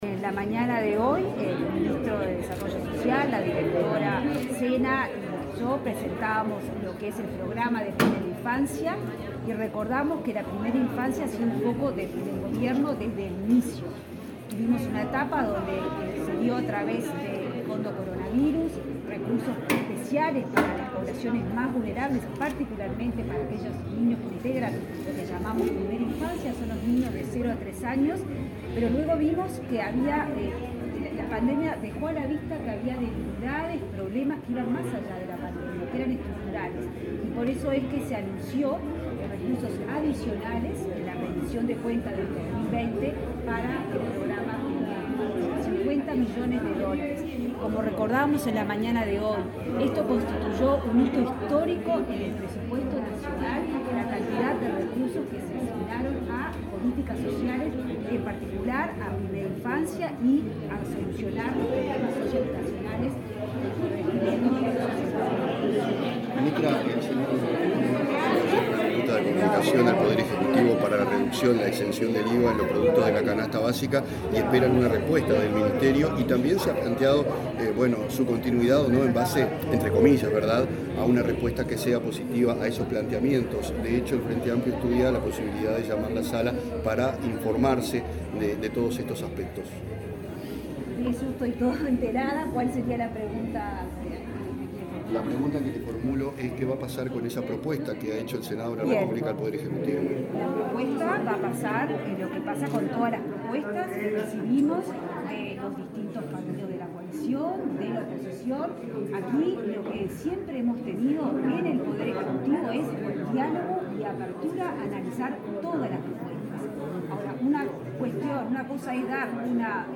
Declaraciones a la prensa de la ministra de Economía y Finanzas, Azucena Arbeleche
Declaraciones a la prensa de la ministra de Economía y Finanzas, Azucena Arbeleche 04/05/2022 Compartir Facebook Twitter Copiar enlace WhatsApp LinkedIn La ministra de Economía y Finanzas participó este miércoles 4, en Montevideo, en el lanzamiento del Mes de la Primera Infancia y, luego, dialogó con la prensa.